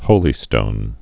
(hōlē-stōn)